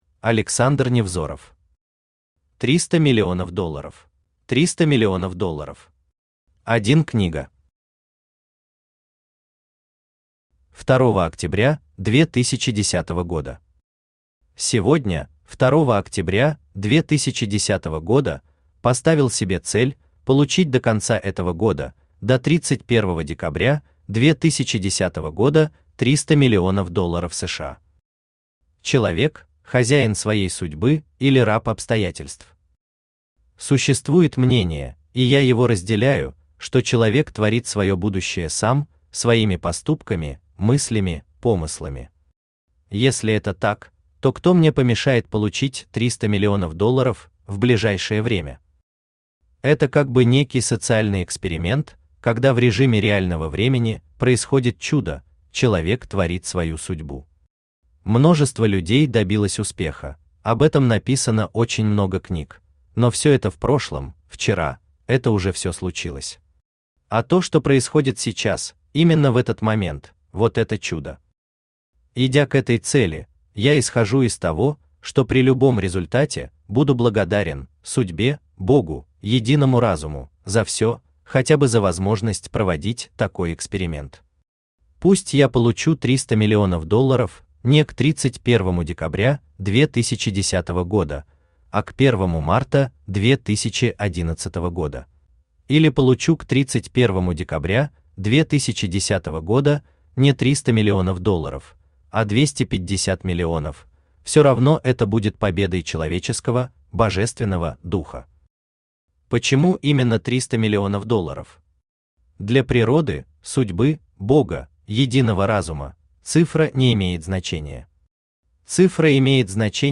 Аудиокнига 300 миллионов долларов | Библиотека аудиокниг
Aудиокнига 300 миллионов долларов Автор Александр Невзоров Читает аудиокнигу Авточтец ЛитРес.